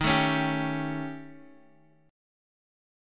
D#7b5 chord